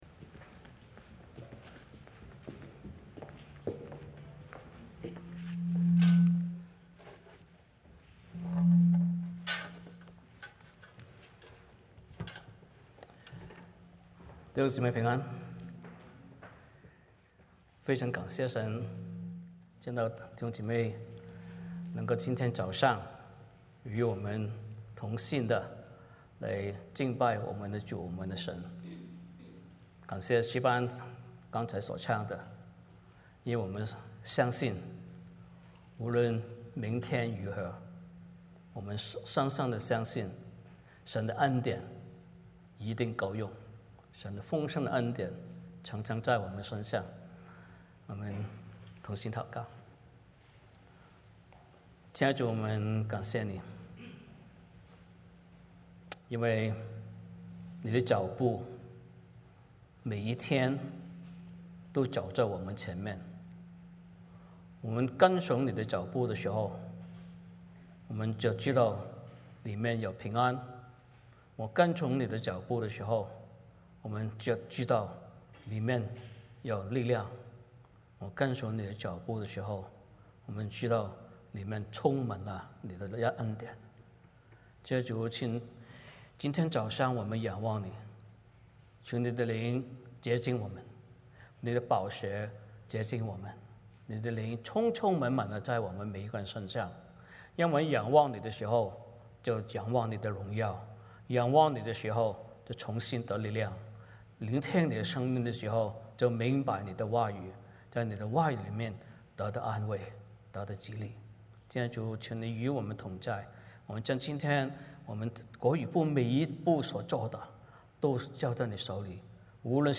哥林多后书 1:1-11 Service Type: 主日崇拜 欢迎大家加入我们的敬拜。